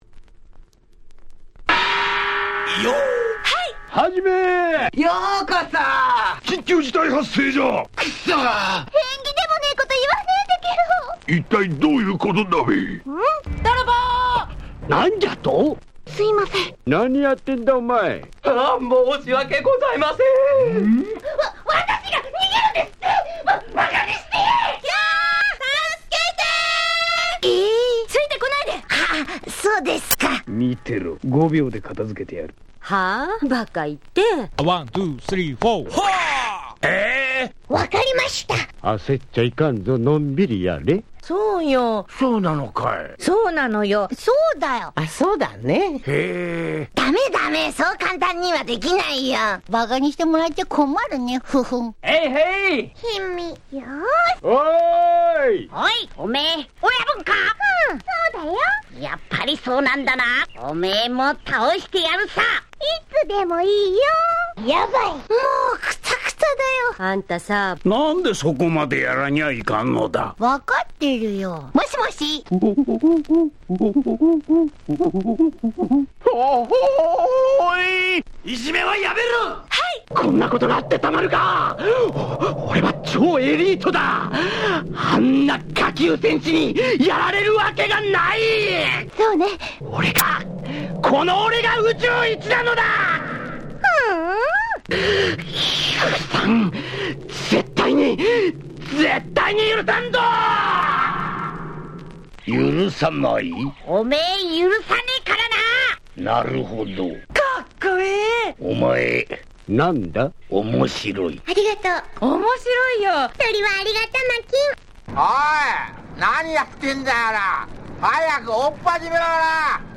07' Very Nice Battle Breaks !!
アニメ系声ネタをふんだんに盛り込んだ和製最強バトブレ！！
なんと言ってもＤＪ向けに作られているのでビートがカナリ効いているところが嬉しいですね。